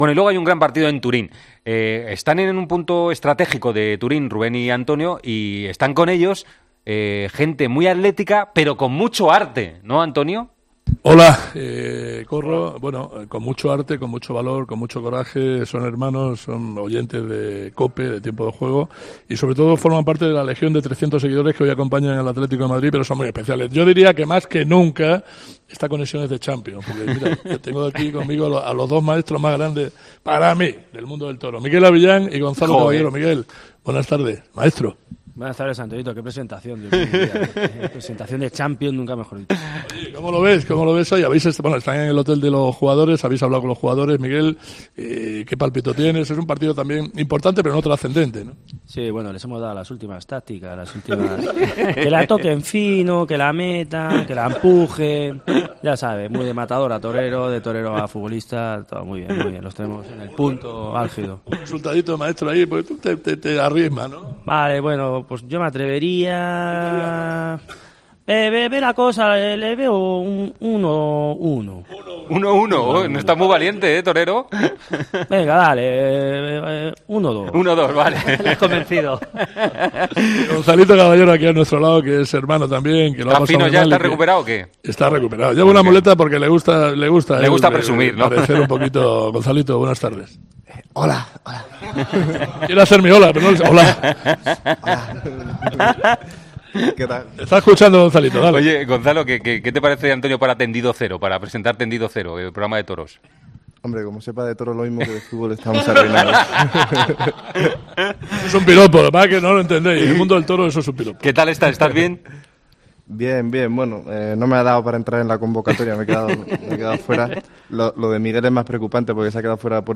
hablan con los toreros Abellán y Caballero que se encuentran en Turín para la previa del Juventus-Atlético.